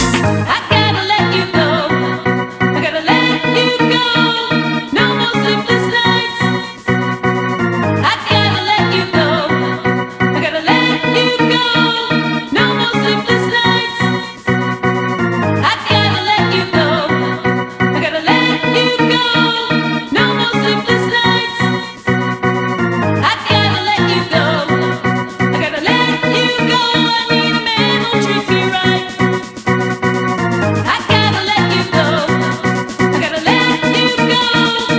Жанр: Танцевальные
# Dance